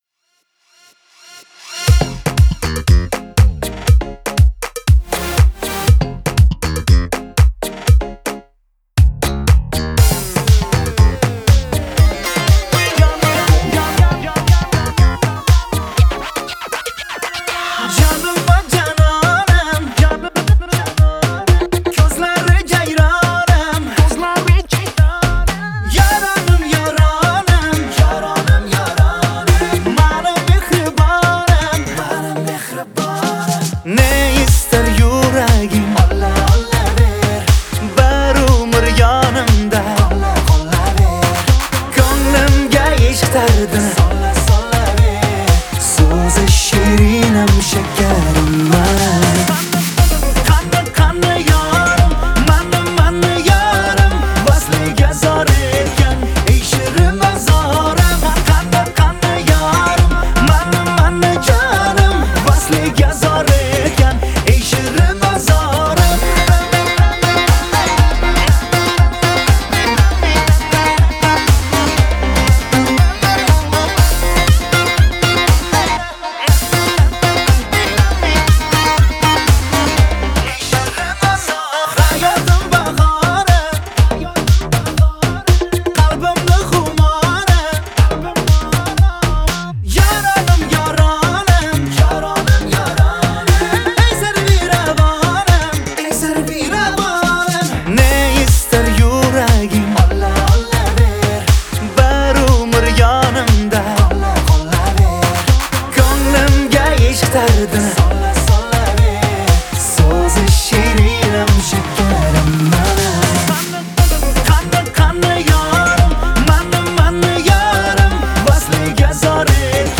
QANI QANI...(попса)